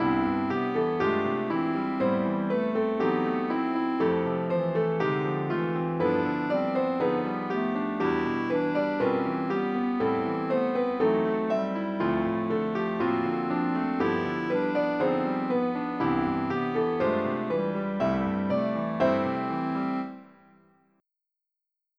Countermelody with contrary motion
Result: Countermelody on a new track using contrary motion against the main melody
piano_Countermelody.wav